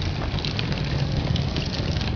fire1.wav